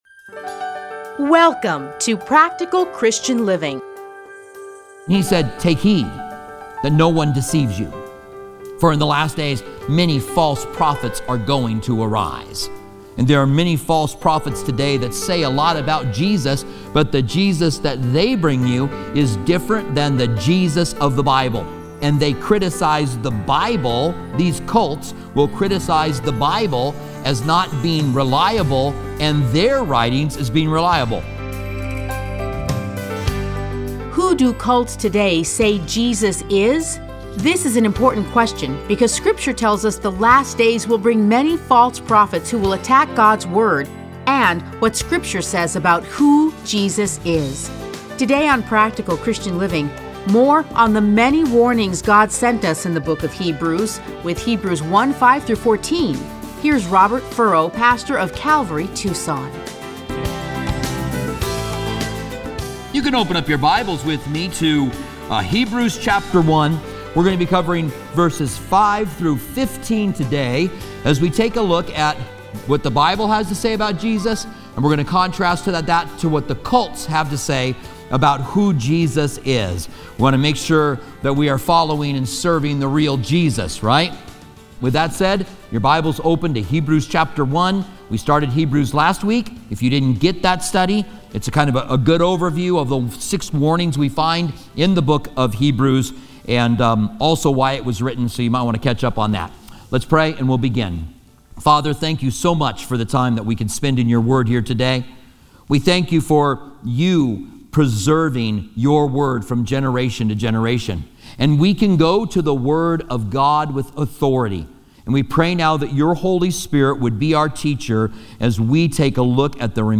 Listen to a teaching from Hebrews 1:5-14.